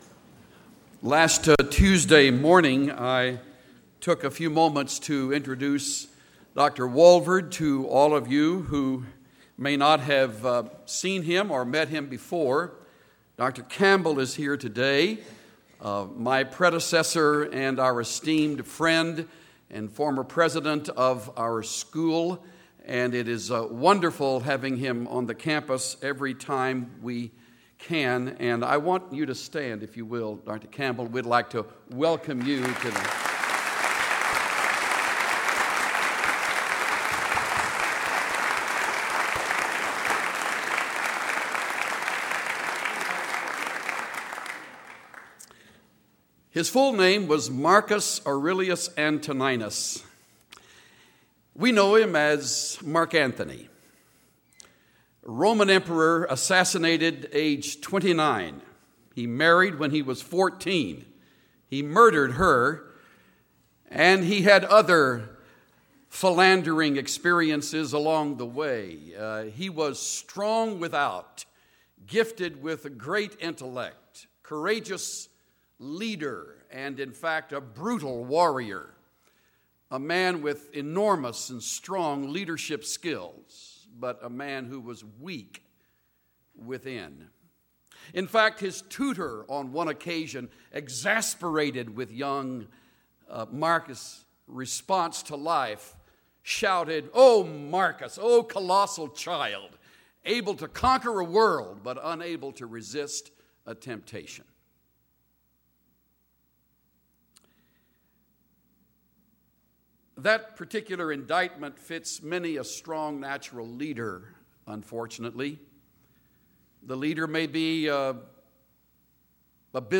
The lecture concludes with a prayer asking for God’s help in living a life of integrity and resisting temptation